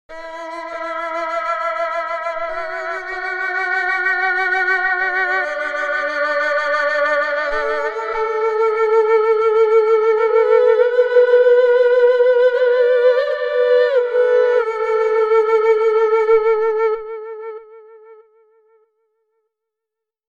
Sad-Huqin.wav 95bpm